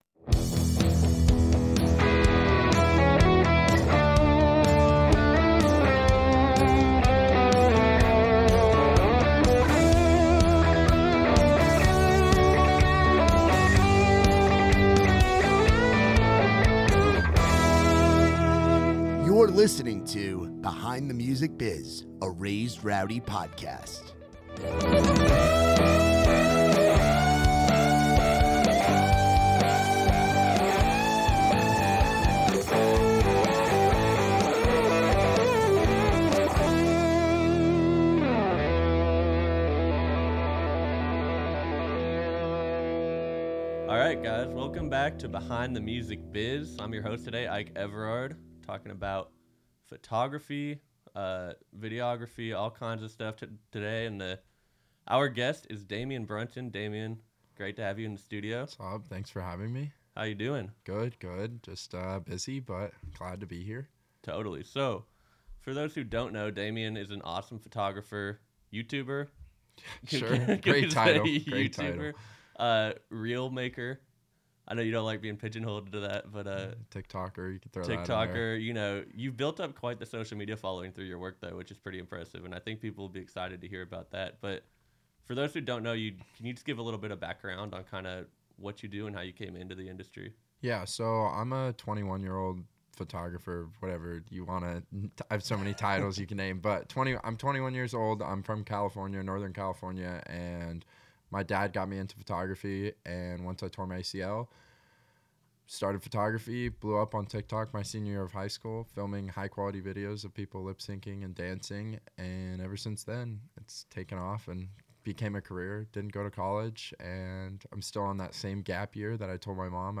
The conversation dives into the value of doing free work to build meaningful industry relationships,